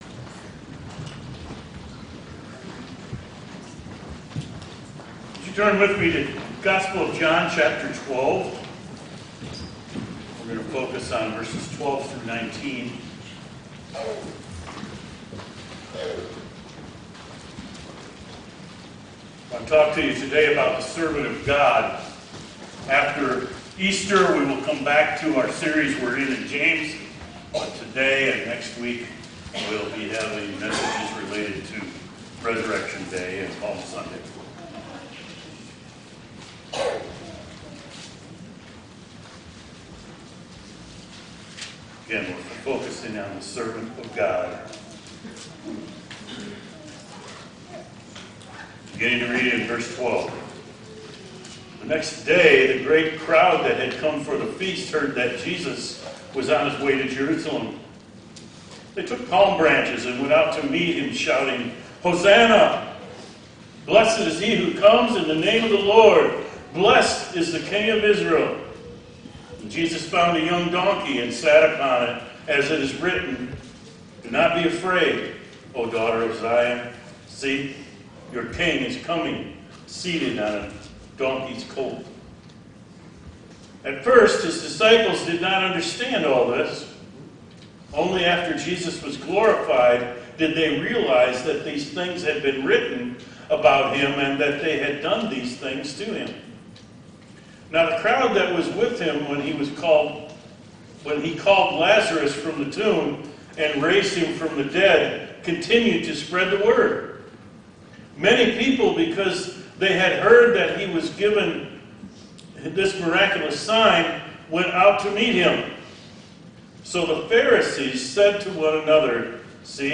palmsunday2025.mp3